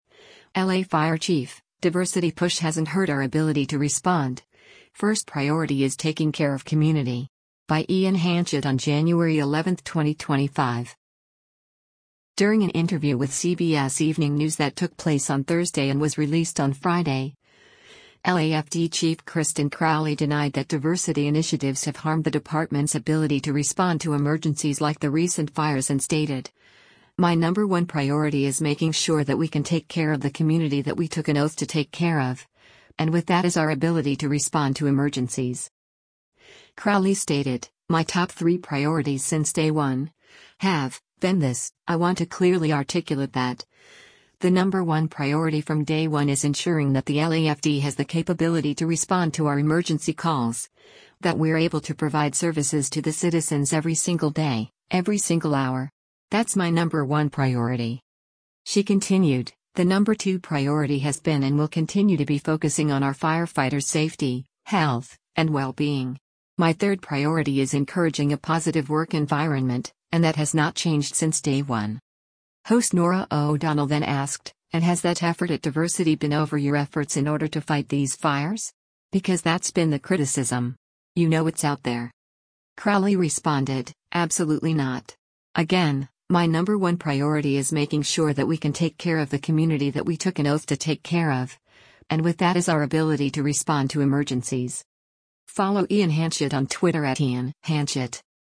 During an interview with “CBS Evening News” that took place on Thursday and was released on Friday, LAFD Chief Kristin Crowley denied that diversity initiatives have harmed the department’s ability to respond to emergencies like the recent fires and stated, “my number one priority is making sure that we can take care of the community that we took an oath to take care of, and with that is our ability to respond to emergencies.”
Host Norah O’Donnell then asked, “And has that effort at diversity been over your efforts in order to fight these fires? Because that’s been the criticism. You know it’s out there.”